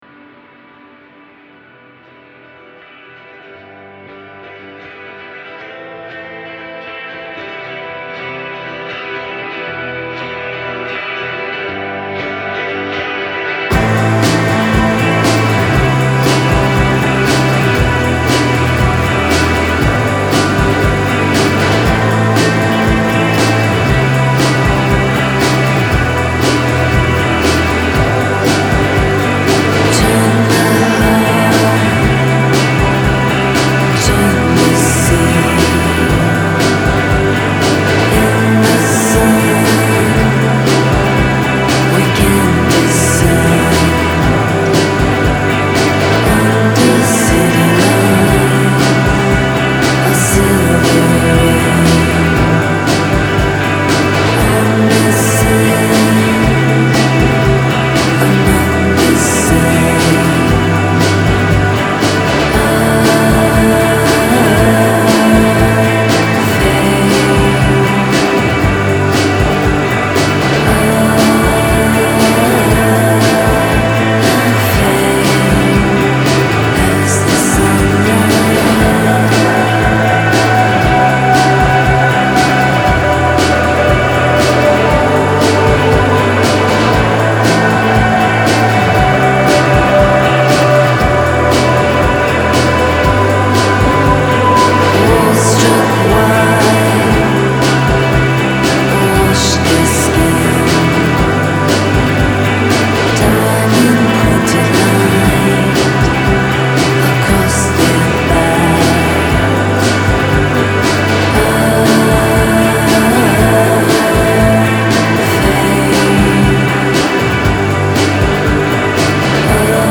gazy, airy